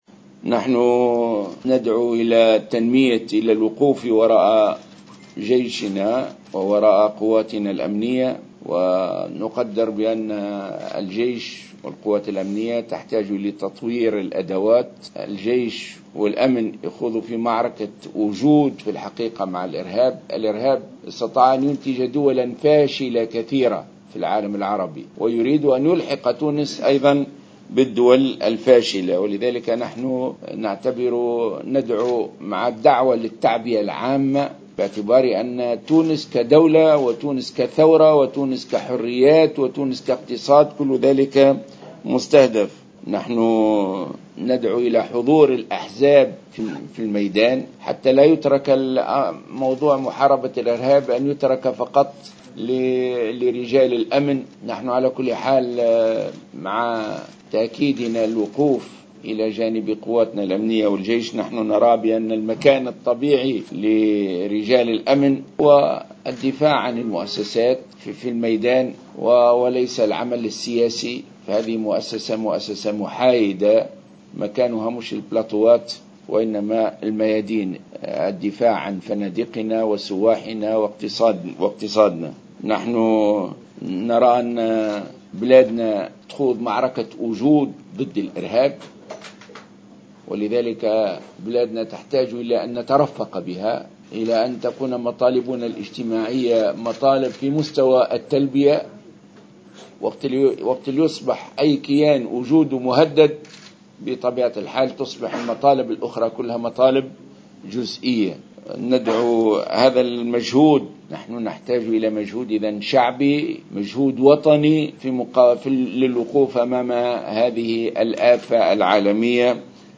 اعتبر رئيس حركة النهضة راشد الغنوشي خلال مؤتمر صحفي عقده بمقر الحركة بالعاصمة اليوم السبت 27 جوان أن تونس تخوض معركة وجود ضد الارهاب الذي طال أمس أحد النزل بالمنطقة السياحية بسوسة.